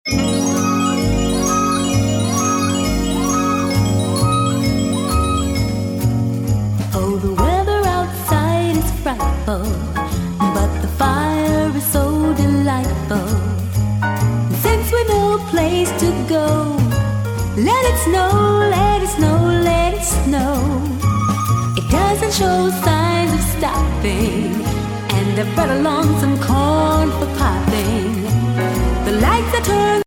Composer: Female
Voicing: PVG Collection W